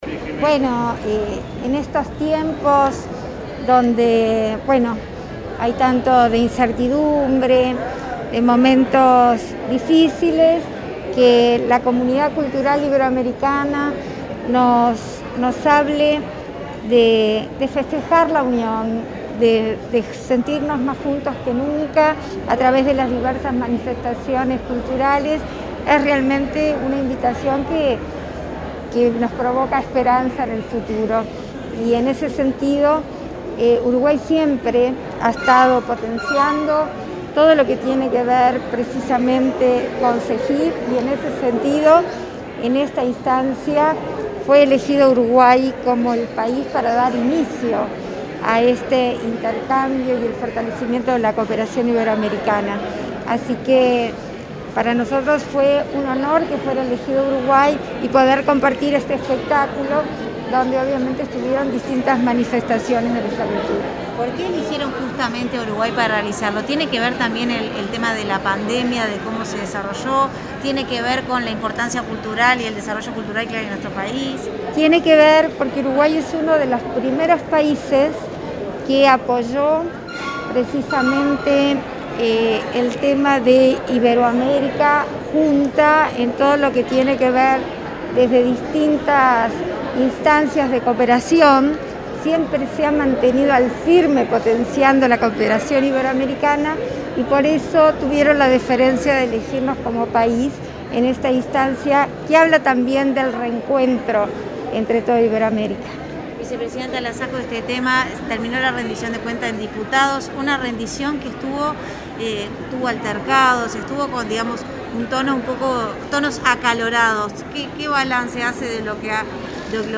Declaraciones a la prensa de la vicepresidenta de la República, Beatriz Argimón
Declaraciones a la prensa de la vicepresidenta de la República, Beatriz Argimón 22/08/2021 Compartir Facebook X Copiar enlace WhatsApp LinkedIn Al finalizar el festival “Iberoamérica viva”, en el marco de los 30 años de las Cumbres Iberoamericanas de Jefes de Estado y de Gobierno, la vicepresidenta Argimón brindó declaraciones a los medios de prensa.